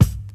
kits/RZA/Kicks/WTC_kYk (23).wav at main